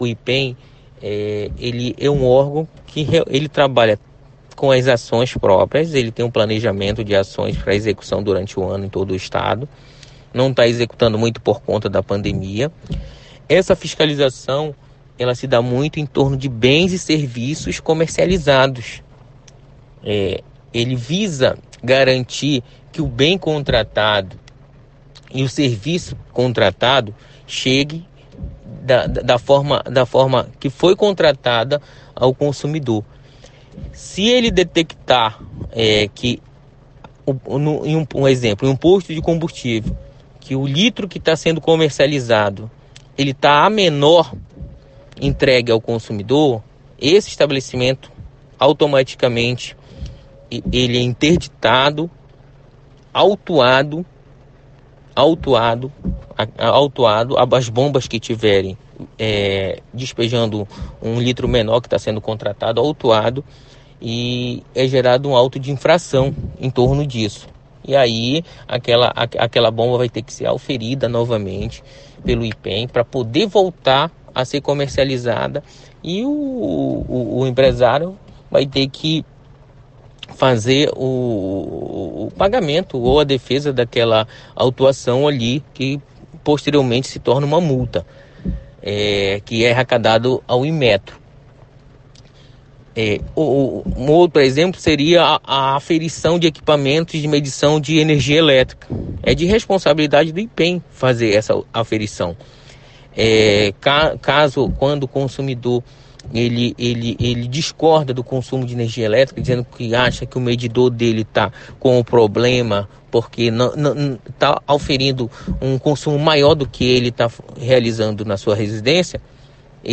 Entrevista_IPEM-1.mp3